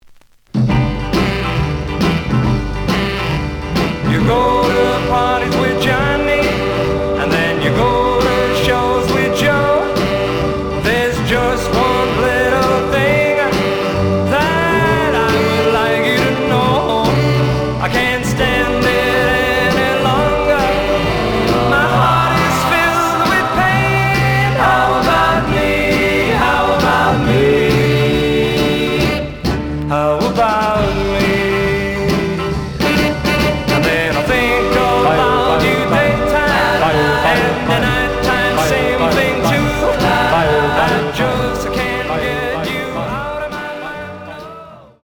The audio sample is recorded from the actual item.
●Genre: Rhythm And Blues / Rock 'n' Roll
Slight edge warp. But doesn't affect playing.